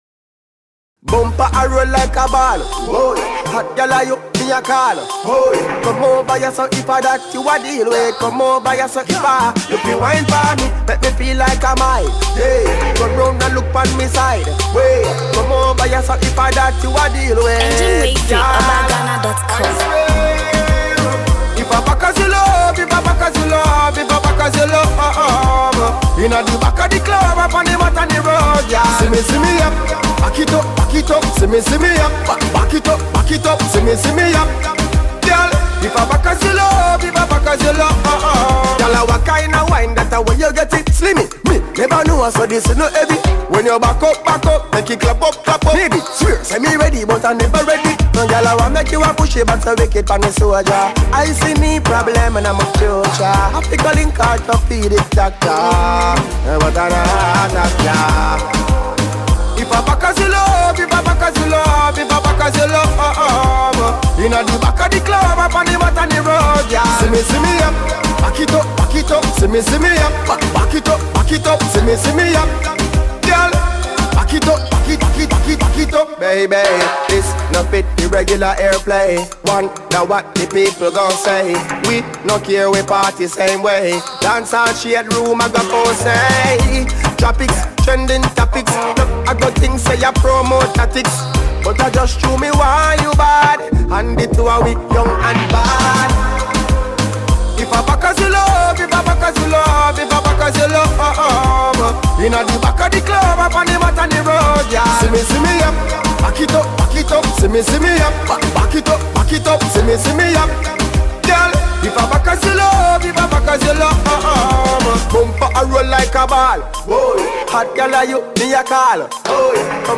Reggae/Dancehall
Enjoy this dope dancehall vibe!!!